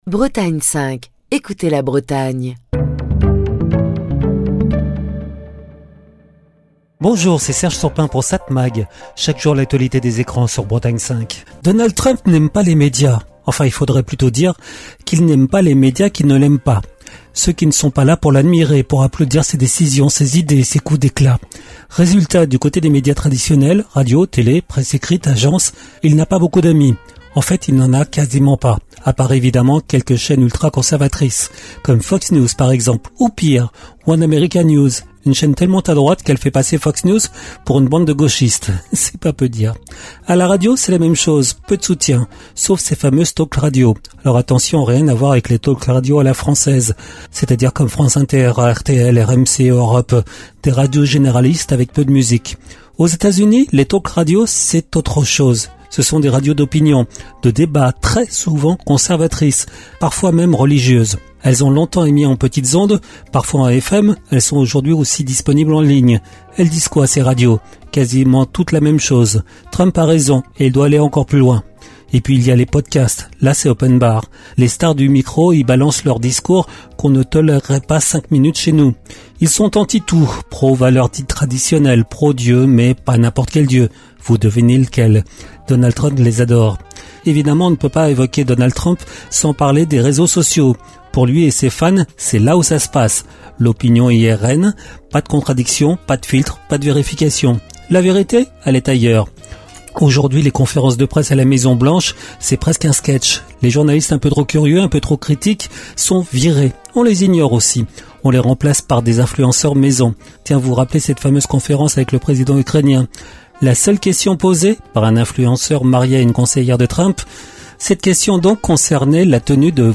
Chronique du 2 mai 2025. Donald Trump n’aime pas les médias.